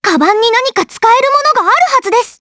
Estelle-Vox_Skill3_jp.wav